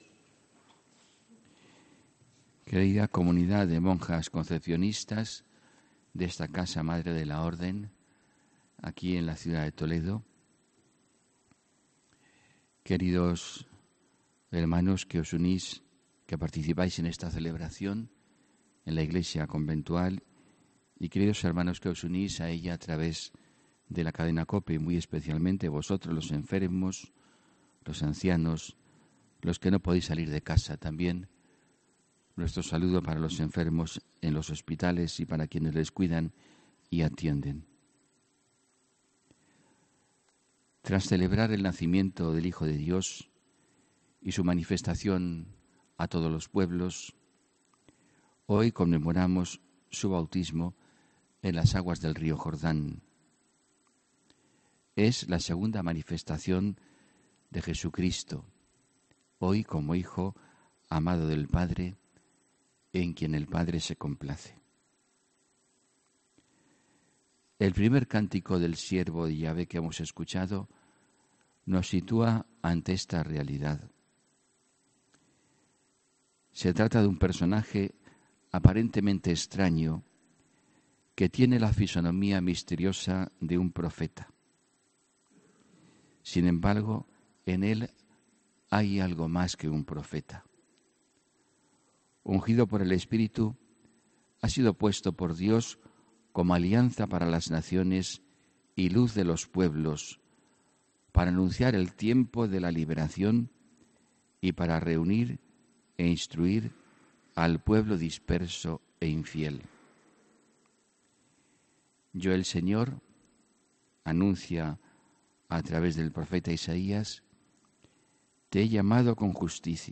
HOMILÍA 13 ENERO 2019